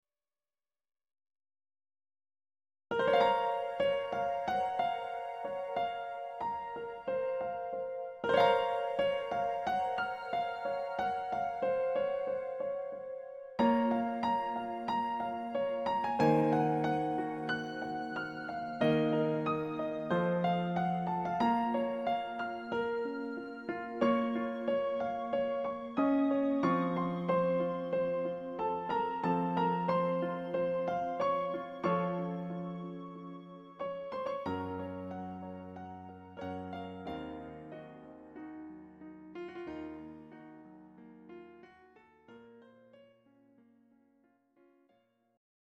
Greek Rock Ballad